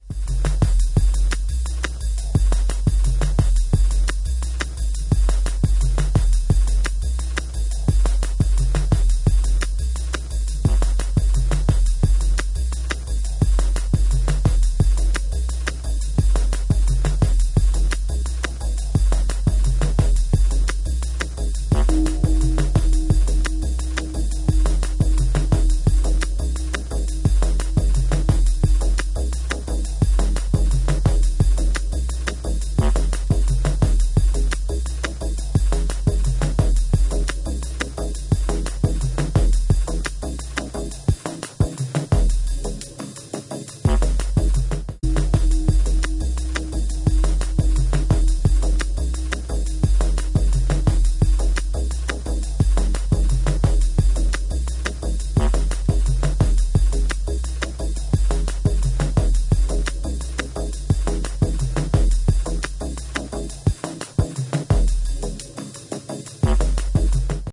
Electronix Bass